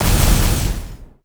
sfx_skill 08_2.wav